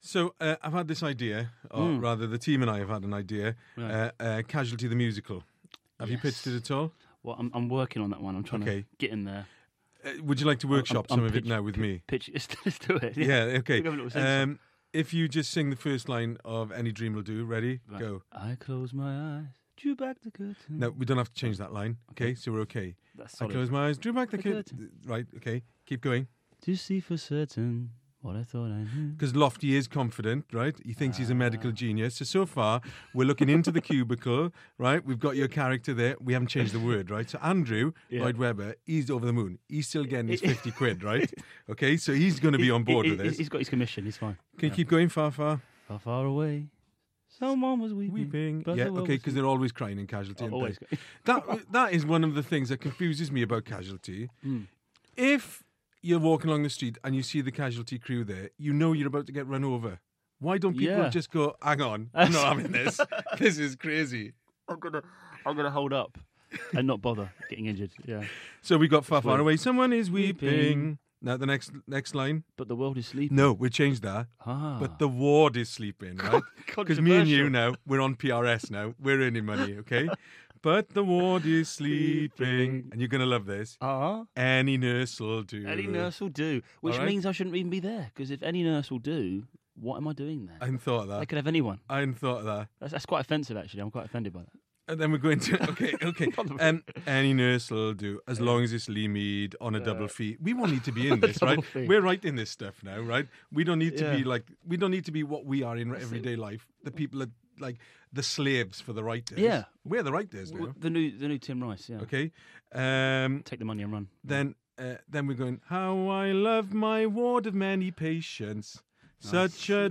his take on a musical episode of the BBC 1 drama